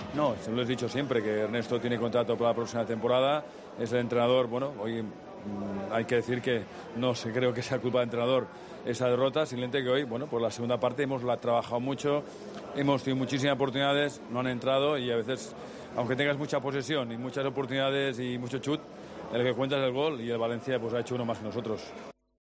AUDIO: El presidente del Barcelona ratificó a su técnico tras perder la final de la Copa del Rey (declaraciones TVE)